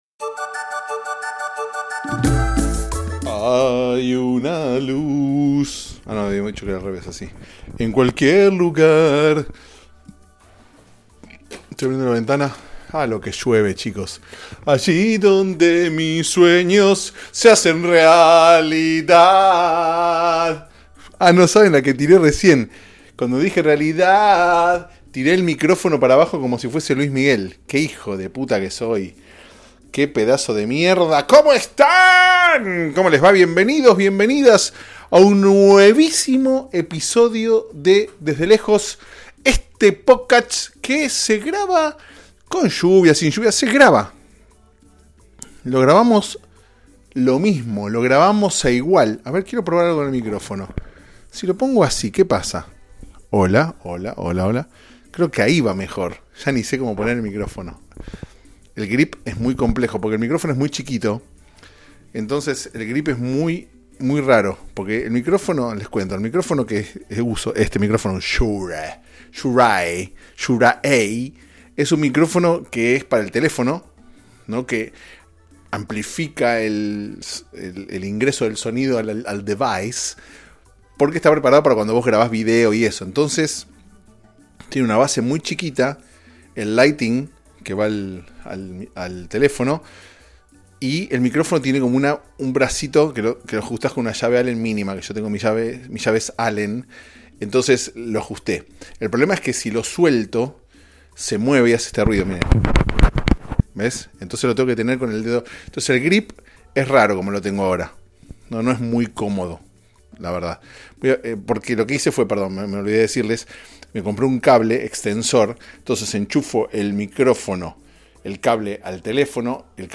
Pensar que la próxima vez que hablemos, ya será Navidad. Mientras tanto, te dejo este episodio que fue grabado bajo la lluvia (casi literal)